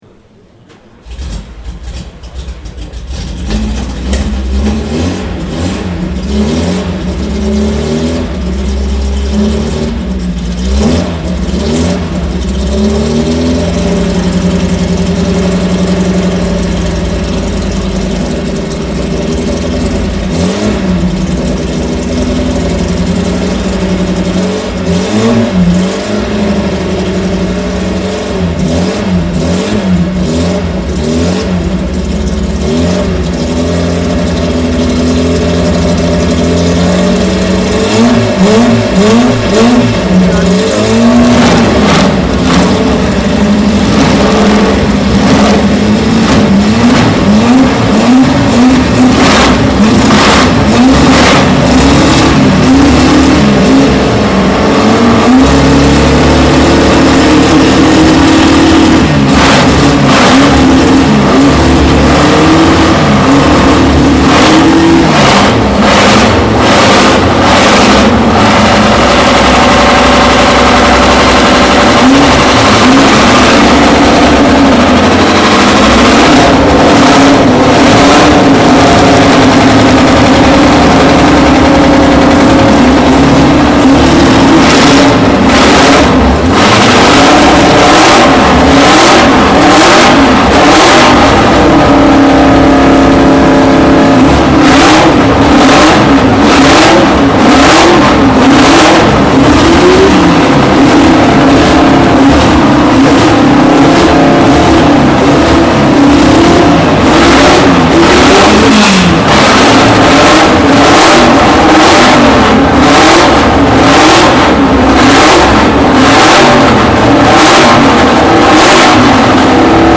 Motorsounds und Tonaufnahmen zu Porsche Fahrzeugen (zufällige Auswahl)